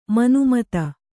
♪ manu mata